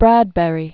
Brad·bur·y